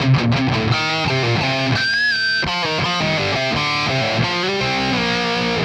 Index of /musicradar/80s-heat-samples/85bpm
AM_RawkGuitar_85-C.wav